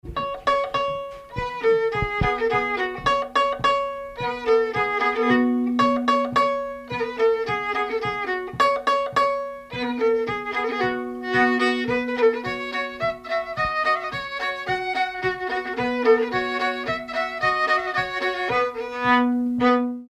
Danse
Saint-Nicolas-la-Chapelle
circonstance : bal, dancerie ;
Pièce musicale inédite